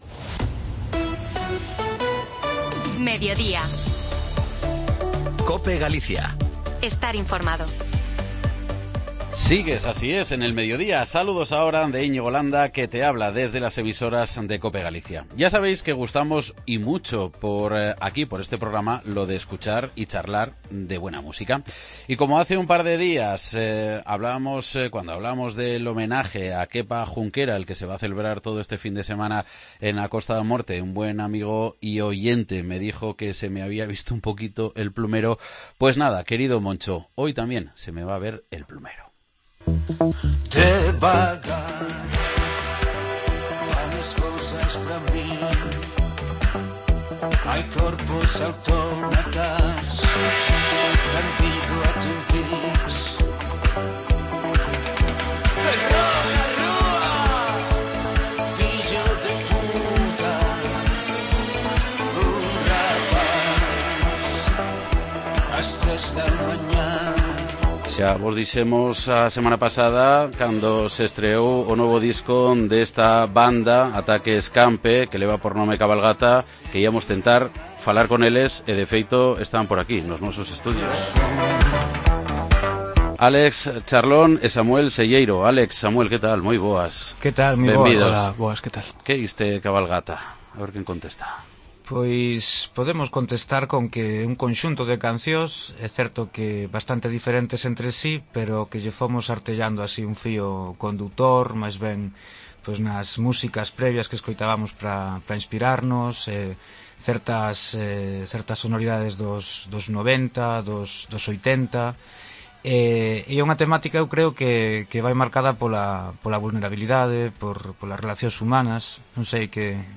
Falamos con dous dos seus membros